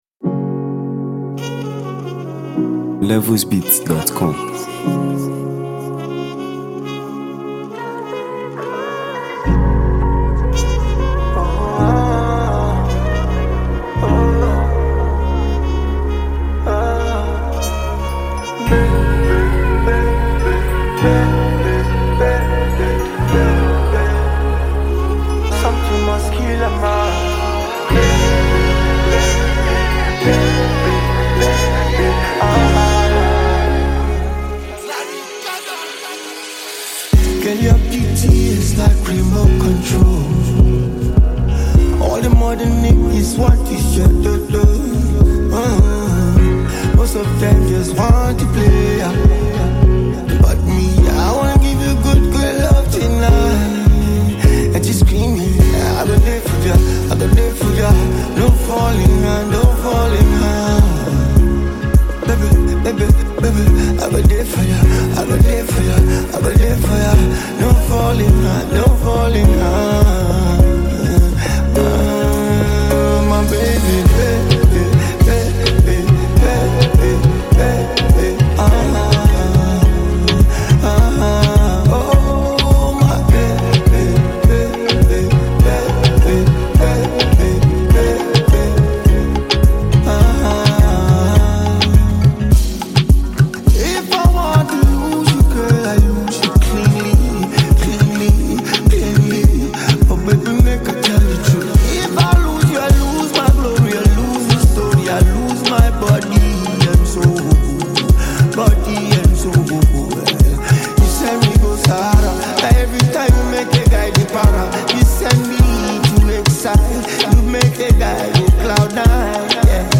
Nigeria Music
soothing vocals